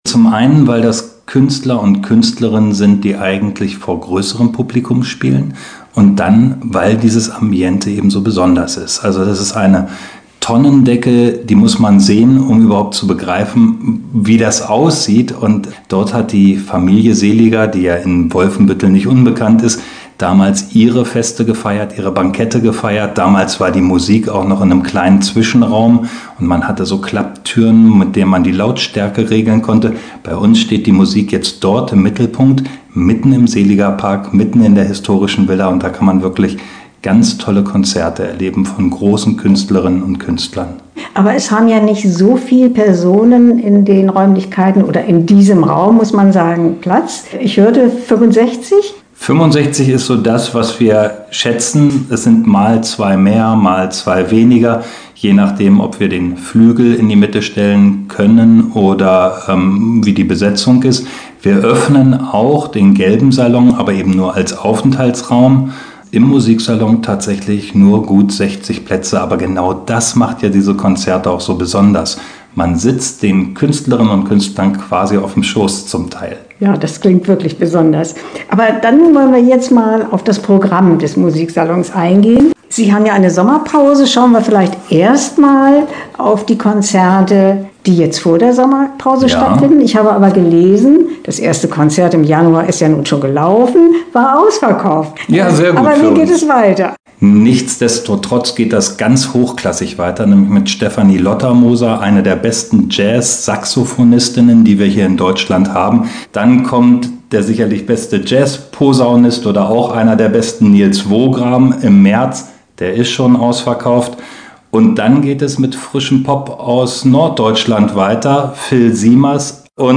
Interview-Musiksalon-WF-2025.mp3